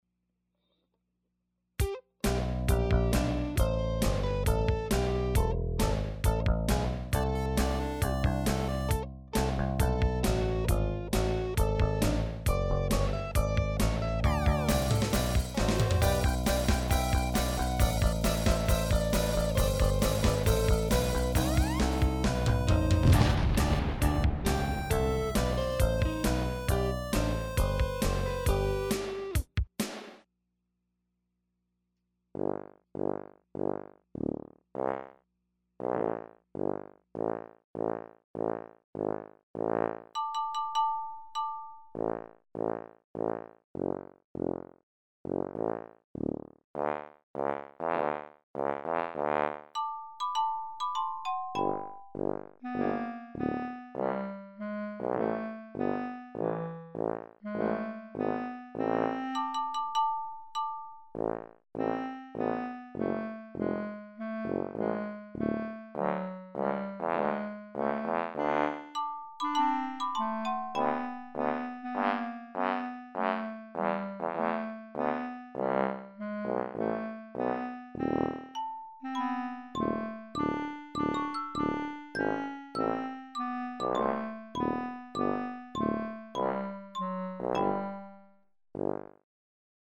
In any case, this is the music for the first scene where the audience is told about golden boy action movie star Grooteclaus Pudd (hence exciting music with explosions), and then actually meet him in the flesh, where it turns out he's become a tubby mustachio'd has-been, hence the walrus reprise of the same theme.